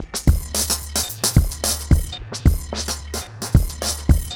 RemixedDrums_110BPM_16.wav